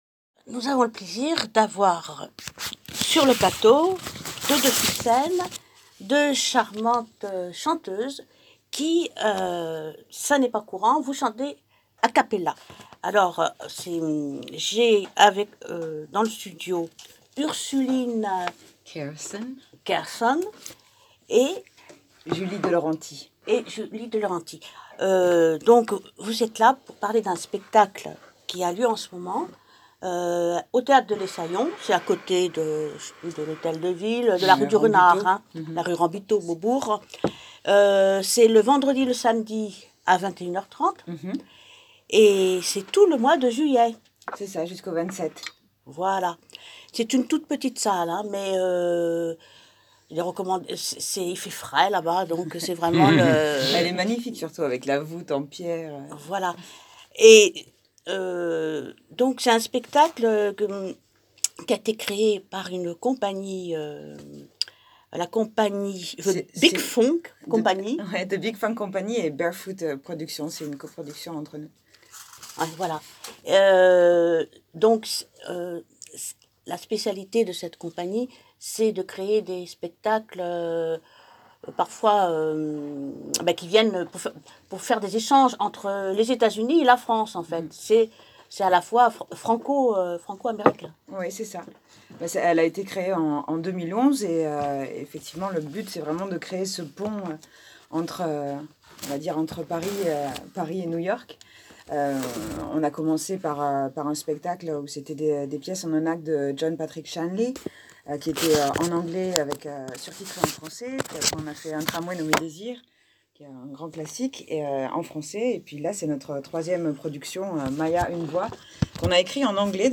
Interview
1d1d8-interview-spectacle-maya-une-voix.m4a